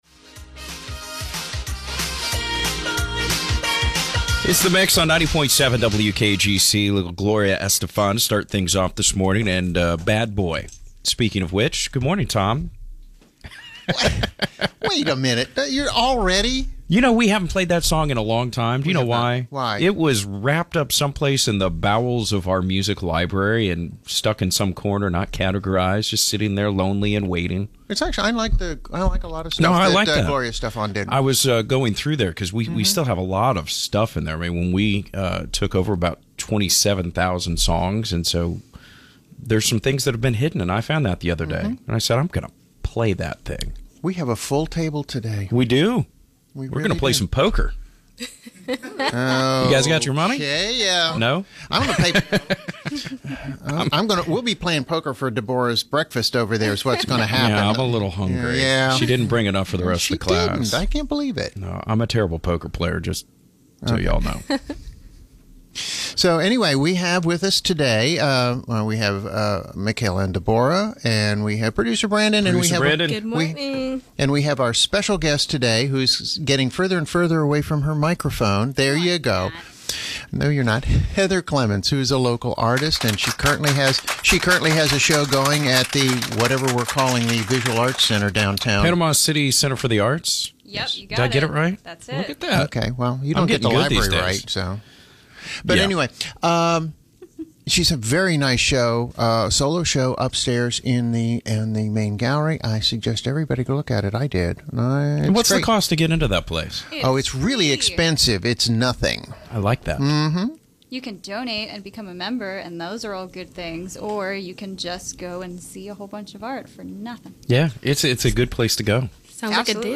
We even have a Vinyl Play to celebrate the weekend, only on The Morning Mix!